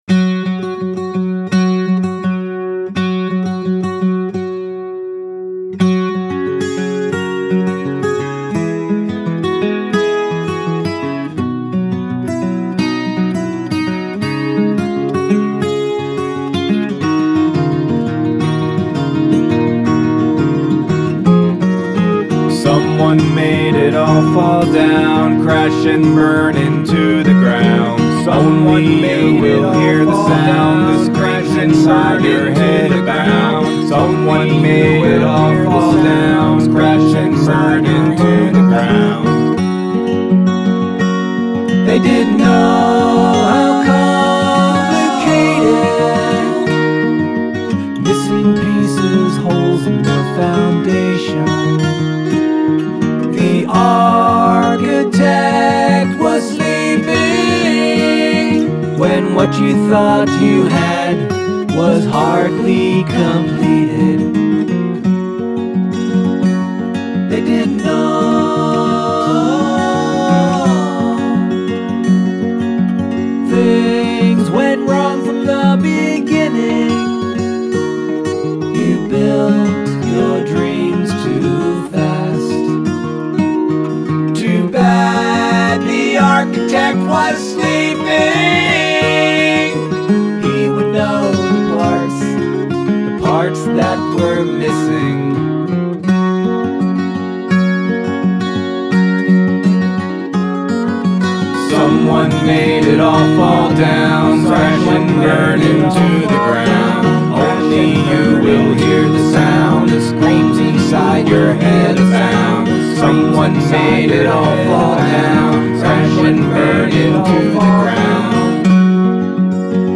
Must include a round.